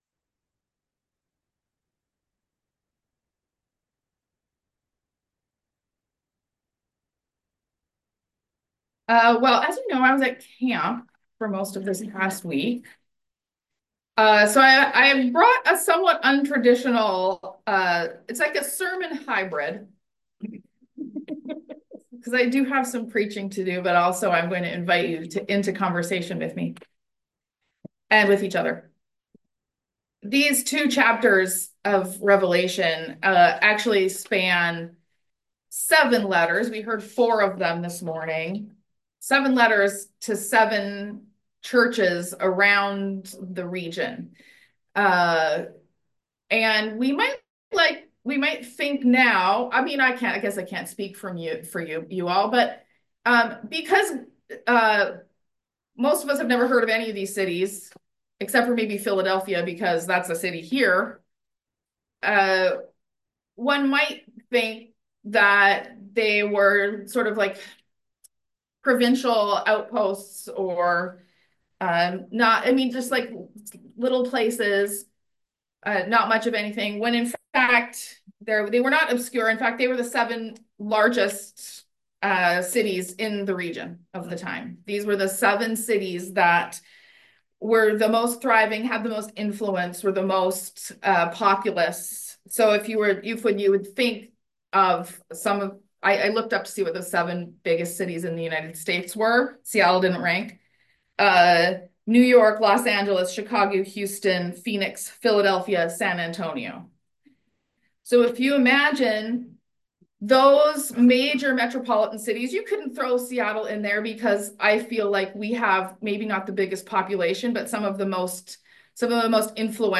A good portion of this service was spent in small group discussion of the text; that portion is not included in the audio recording.